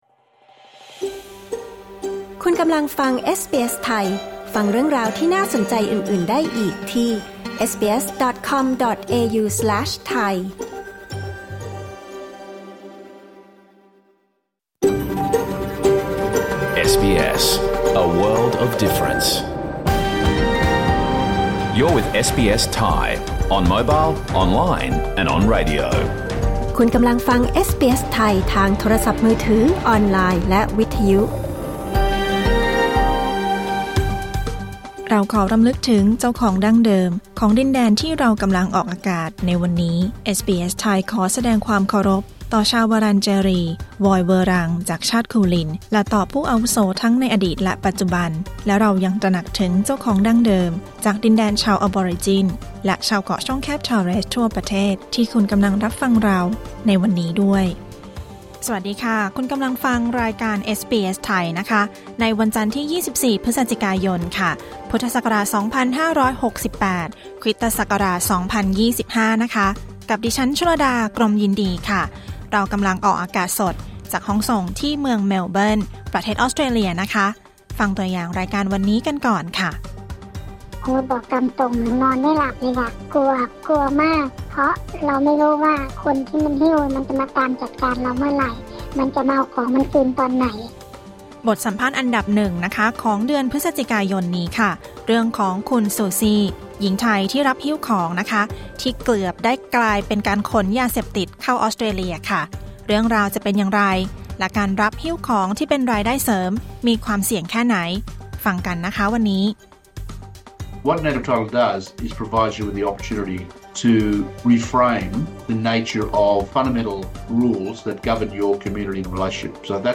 รายการสด 24 พฤศจิกายน 2568
รายการเอสบีเอส ไทย ออกอากาศทุกวันจันทร์และวันพฤหัสบดี รายการสดเวลา 14.00 น. และรายการย้อนหลังเวลา 22.00 น.